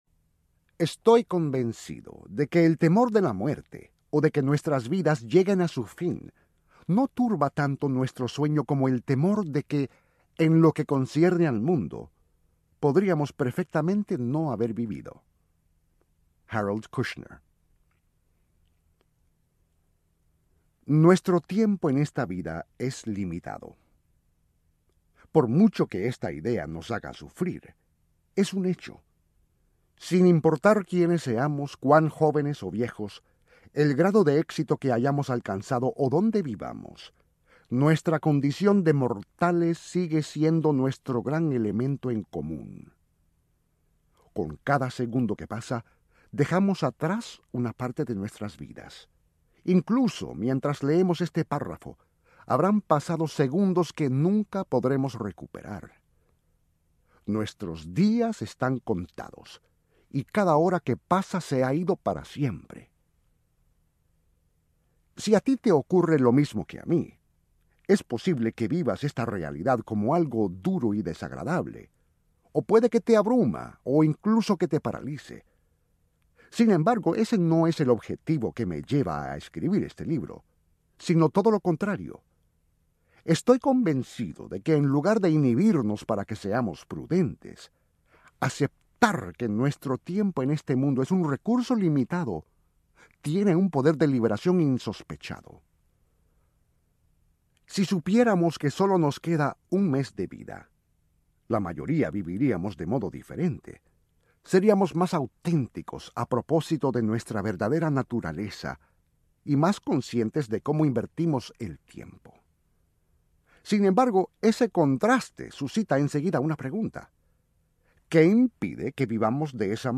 Un mes para vivir Audiobook
Narrator
9.5 Hrs. – Unabridged